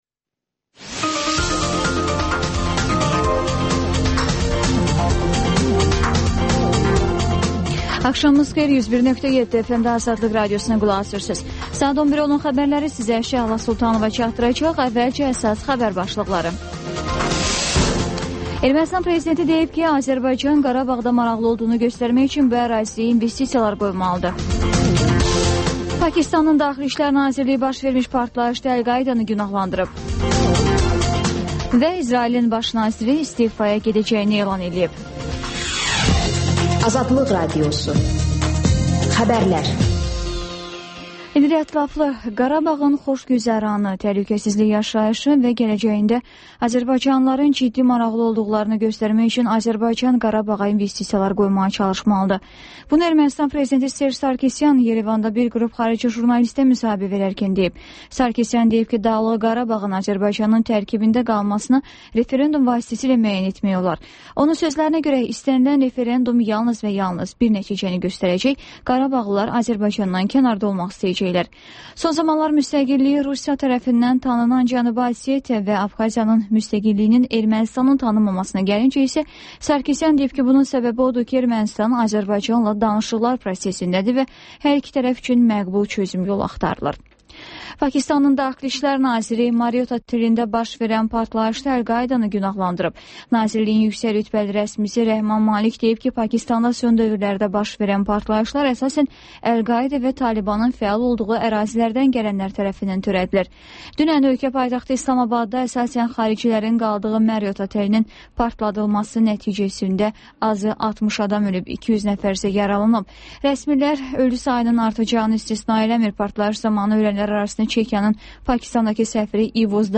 Xəbərlər, RAP-TIME: Gənclərin musiqi verilişi